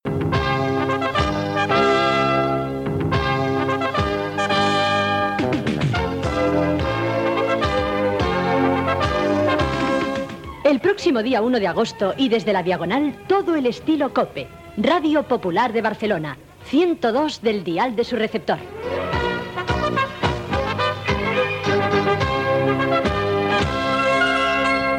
Promoció de l'inici d'emissions en FM de Radio Popular de Barcelona, el dia 1 d'agost de 1988